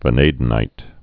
(və-nādn-īt, -năd-, vănə-dēnīt)